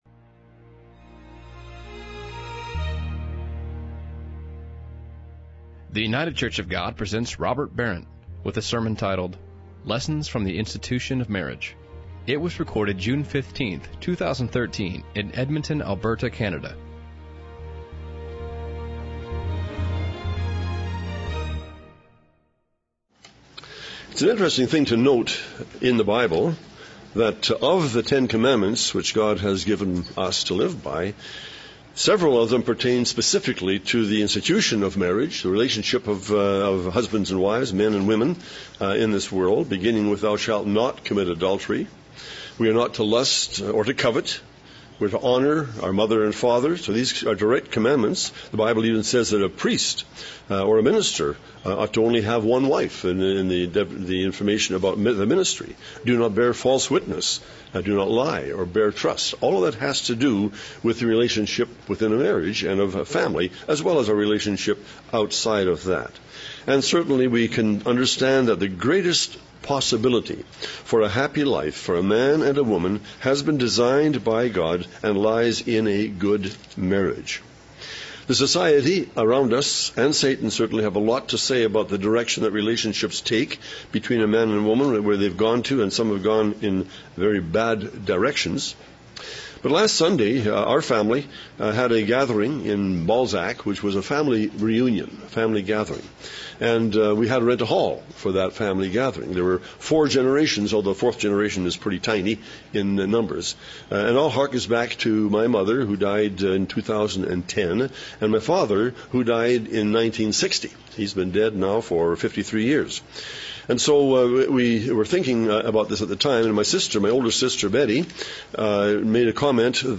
There are a lot of lessons to learn from marriage. This sermon examines those lessons that show that marriage is very important to God.